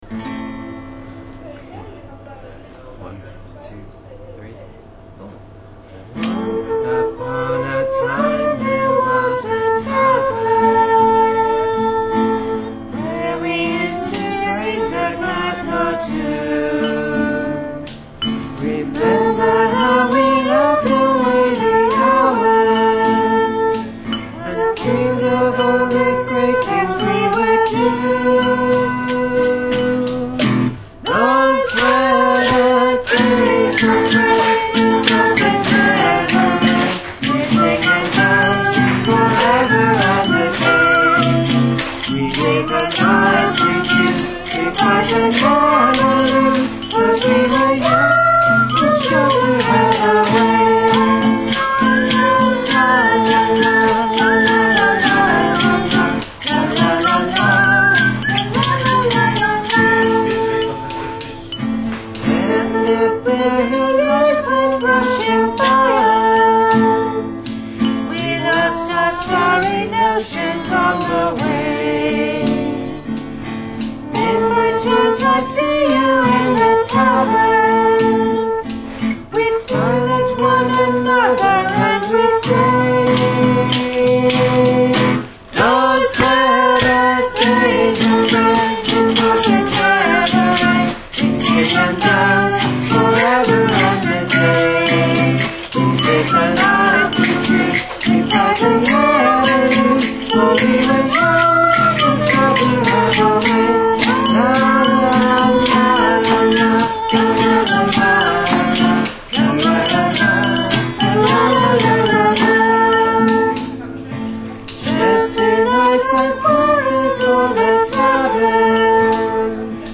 Orkestra | Dünyaya Seslen
tmrorkestra.mp3